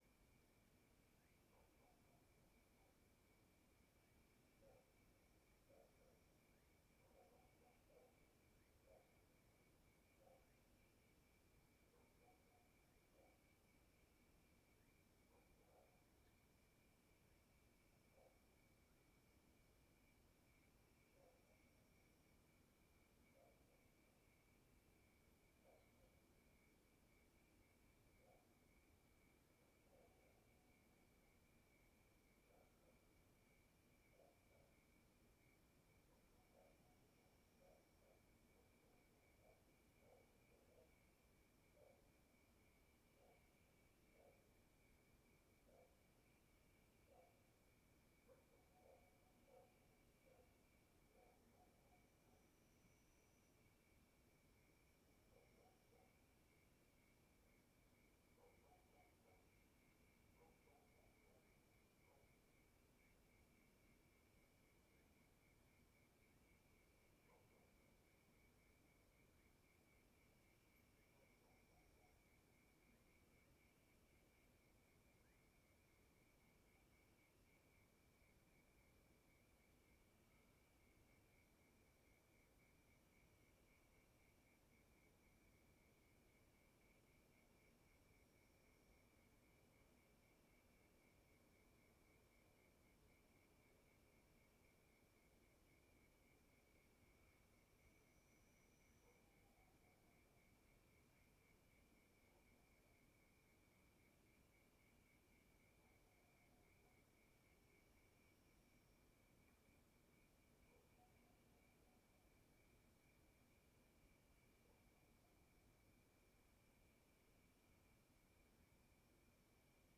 CSC-03-011-LE - Ambiencia rural bem silenciosa de madrugada com grilo e cachorro latindo.wav